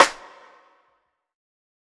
006_Lo-Fi Ambient Snare.wav